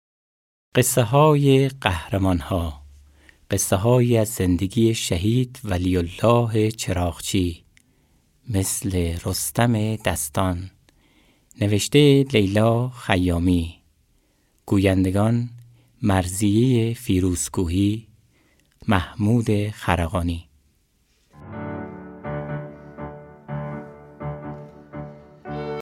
معرفی کتاب صوتی«مثل رستم دستان»